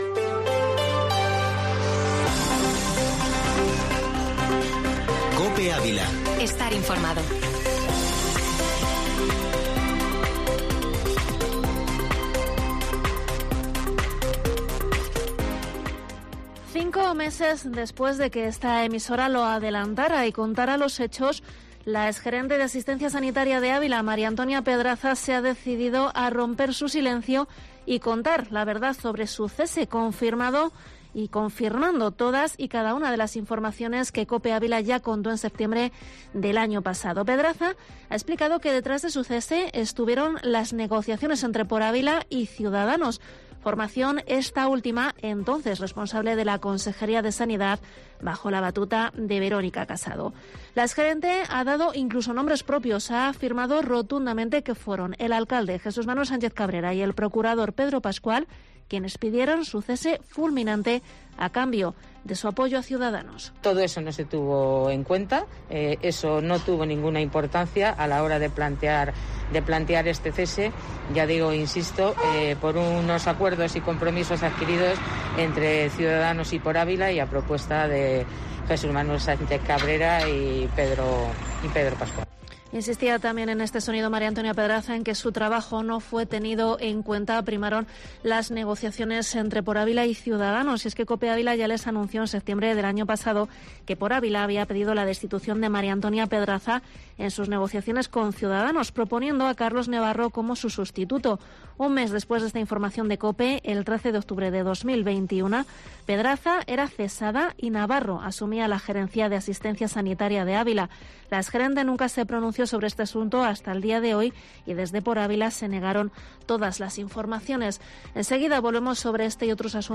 Informativo Mediodía COPE en Ávila 9/2/22